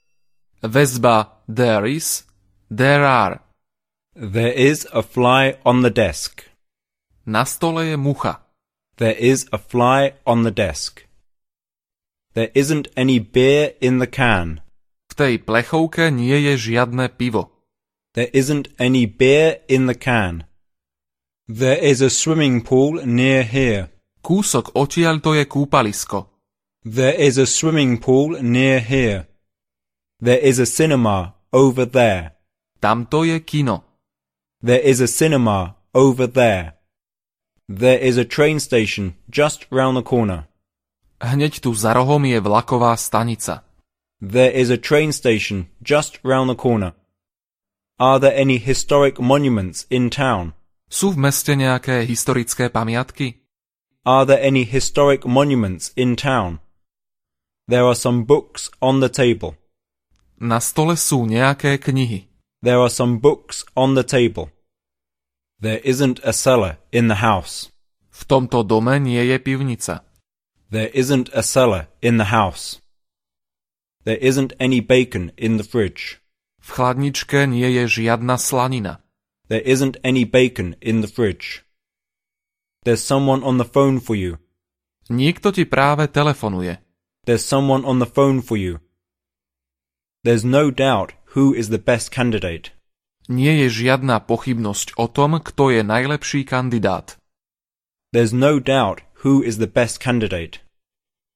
Ukázka z knihy
Každú vetu počujete najprv po anglicky, potom v slovenskom preklade a znovu v originálnom znení.
Príkladové vety nahovoril rodený Angličan.